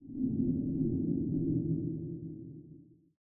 Ambient11.ogg